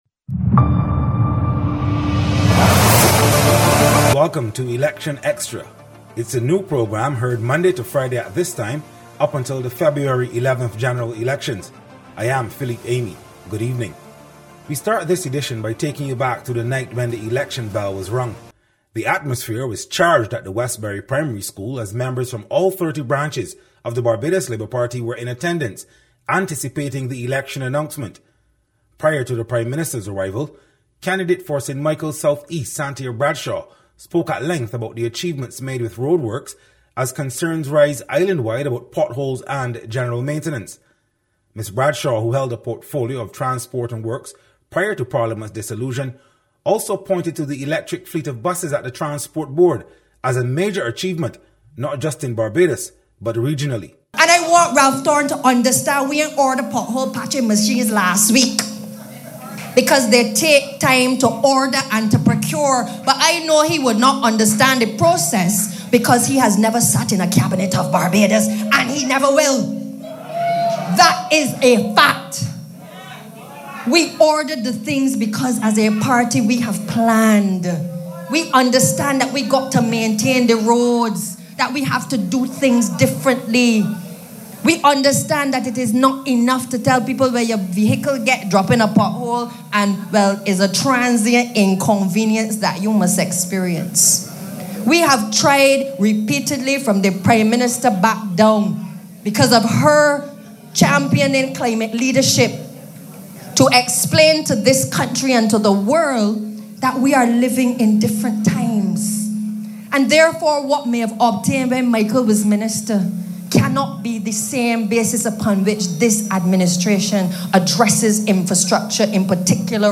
This edition of Election Xtra revisited the night the election was called, capturing the charged atmosphere at Westbury Primary School as Barbados Labour Party supporters gathered from across all constituencies.